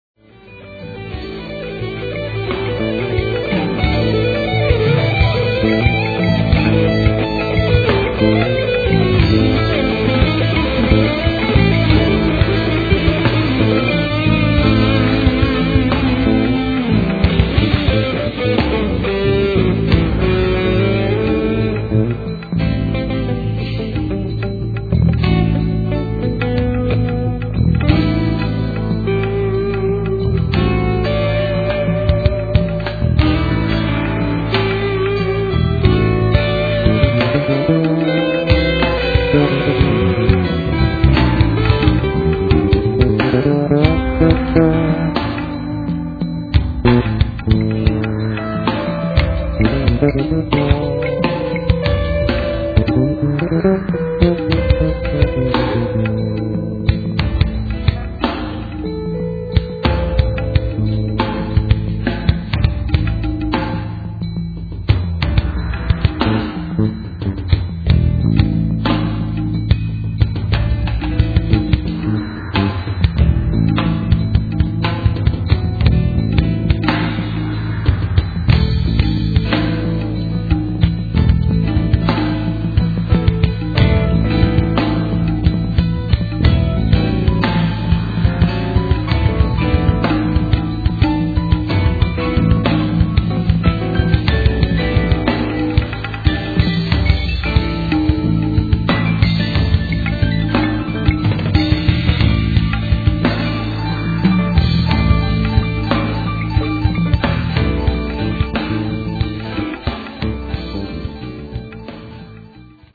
recorded at Midtown Recording